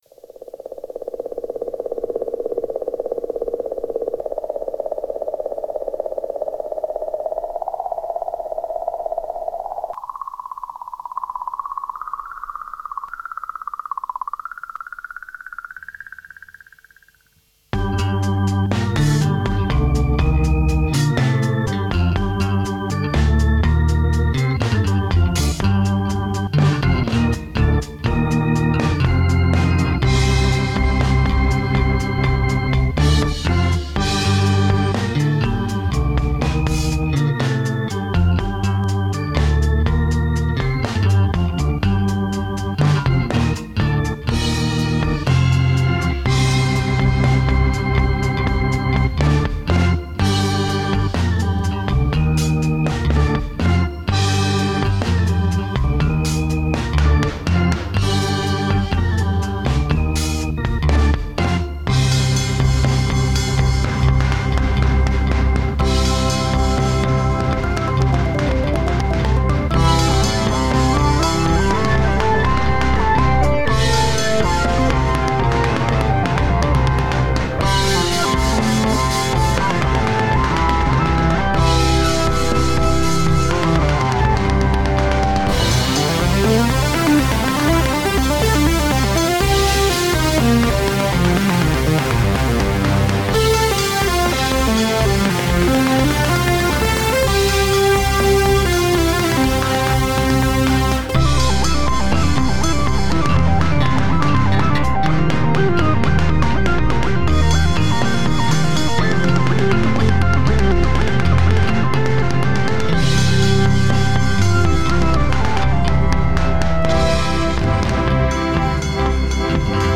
Téma: Pokus o art rock
Nástroje okrem basy sú nahrané s Yourock midi guitar cez Toneport UX2 (samozrejme dodatočné editácie myšou), bicie Addictive Drums.
Je to niečo pre moju geriatrickú dušu, pripomína mi to trochu ranných Genesis s Yesovskou basou, celkom fajn dielko. blush